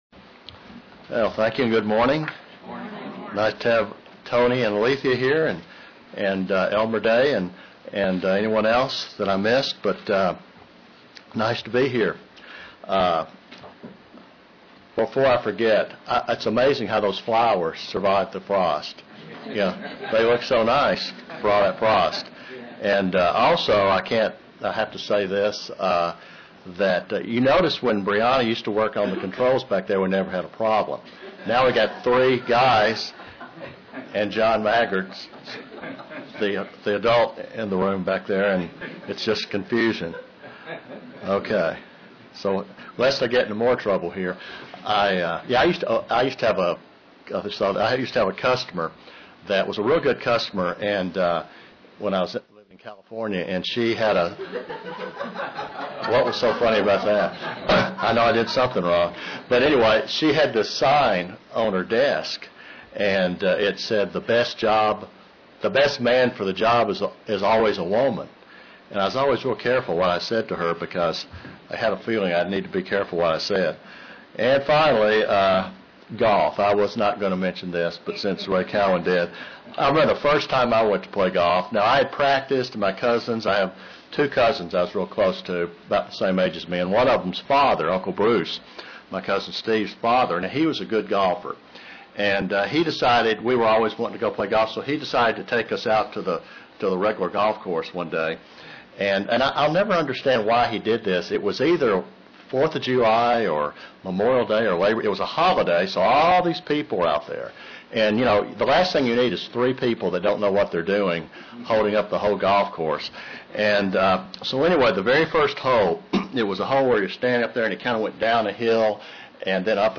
Print History of the holiday of halloween UCG Sermon Studying the bible?